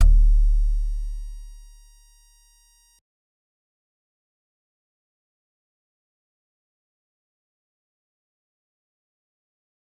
G_Musicbox-D1-pp.wav